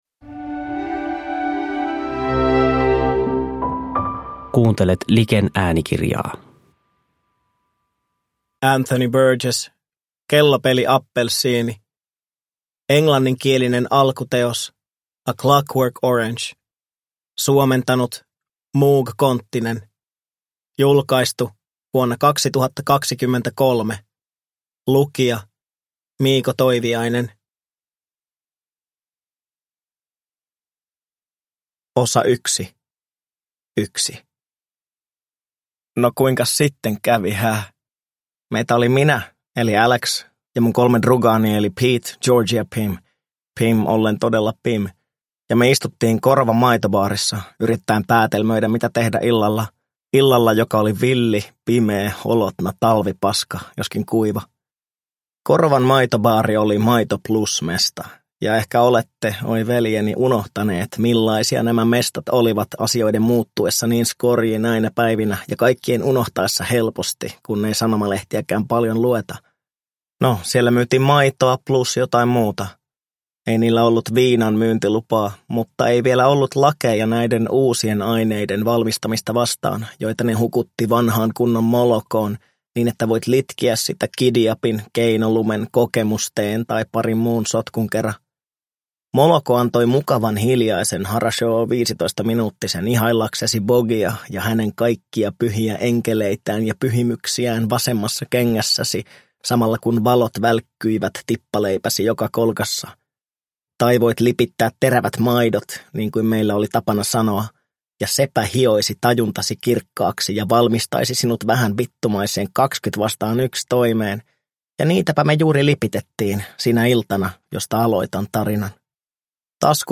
Kellopeli appelsiini – Ljudbok